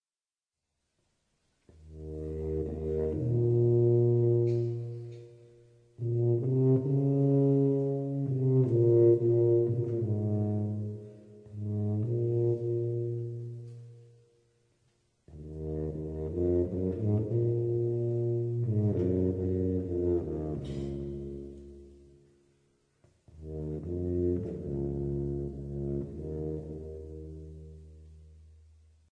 É unha familia moi poderosa pola súa potencia de son, xa que este material conduce moi ben as vibracións.
1. A é o instrumento da familia que presenta o rexitro máis grave, e polo tanto a máis grande.
tuba.mp3